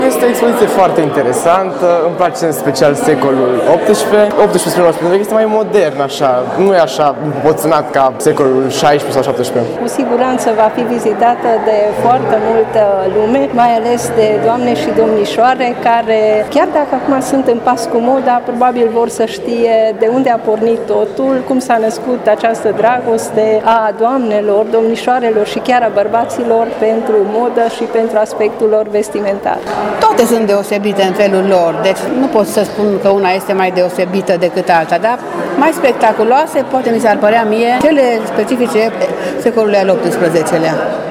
Tîrgumureșenii care au trecut astăzi pragul muzeului au fost impresionați de exponate, mai ales de cele care reprezintă secolul al VIII-lea.